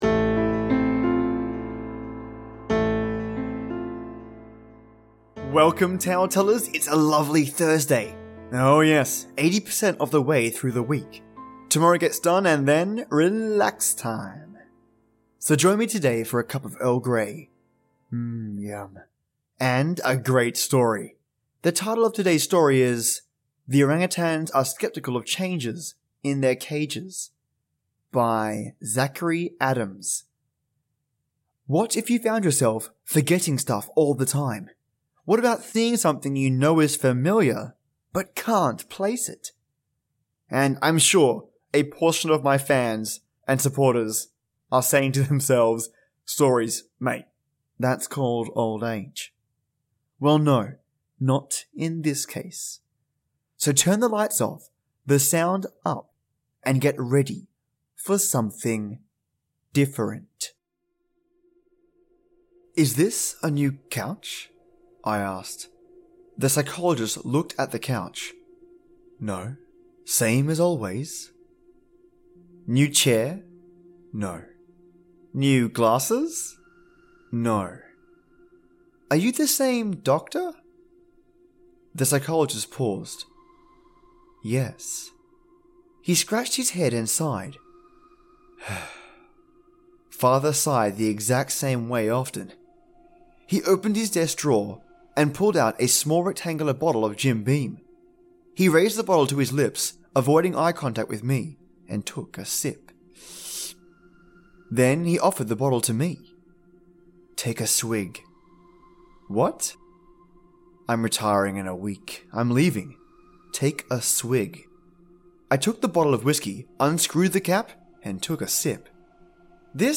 The story explores what it means to notice, pay attention, and understand the world around you and those that are in it - and the frustration in knowing that something is always missed. This episode is a heavy dialogue story, so I hope you like it :D Enjoy you fantastic fans!